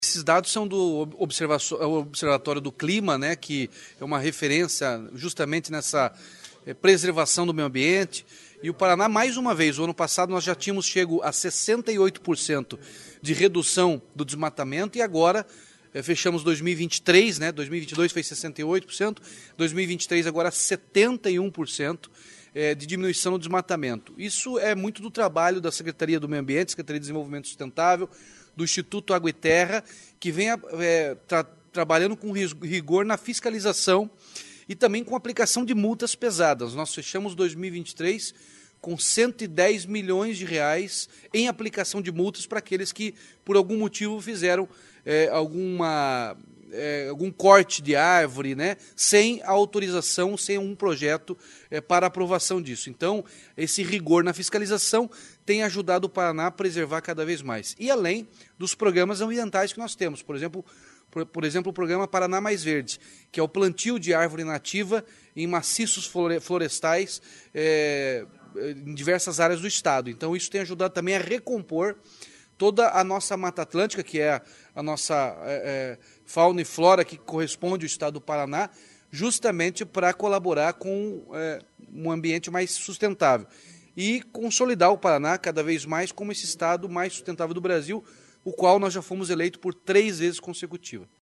Sonora do governador Ratinho Junior sobre a redução de 71,5% no desmatamento ilegal da Mata Atlântica em 2023